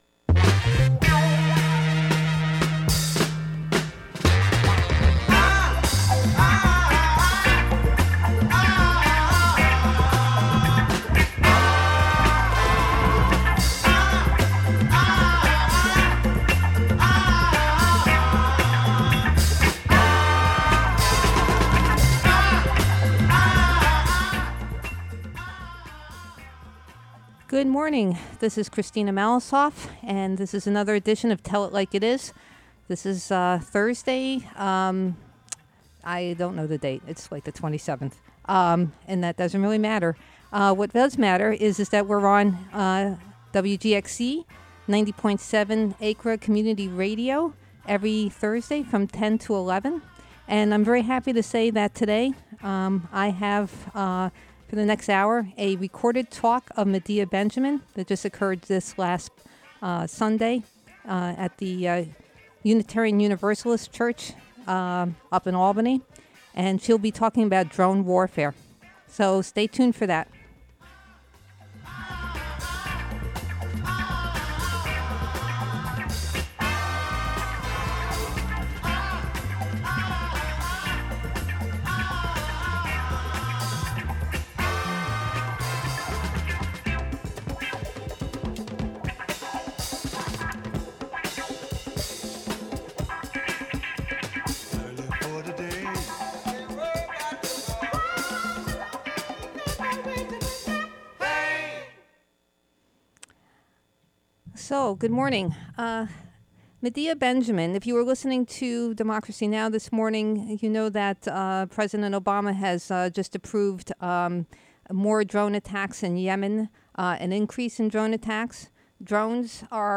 A talk by Medea Benjamin, co-founder of Code Pink, on her book "Drone Warfare." She spoke Sunday, April 22nd at the First Universalist Unitarian Society of Albany.